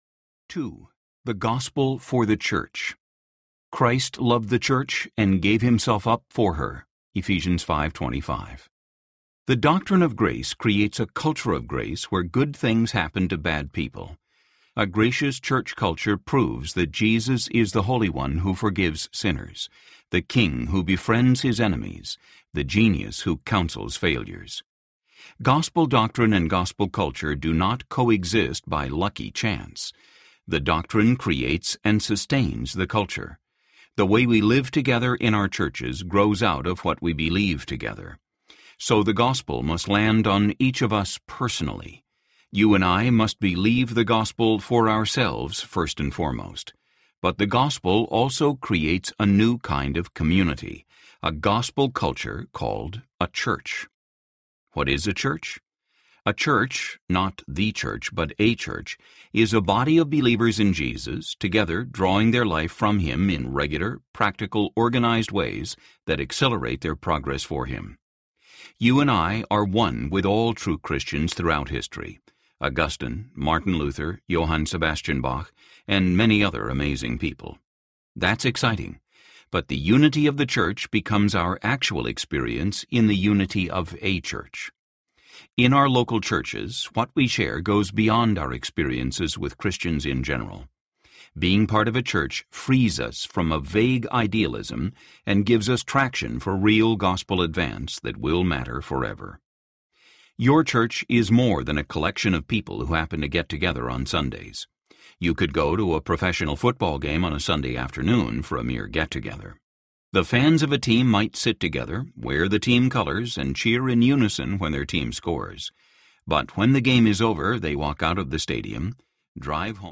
The Gospel (9Marks Series) Audiobook
Narrator
2.75 Hrs. – Unabridged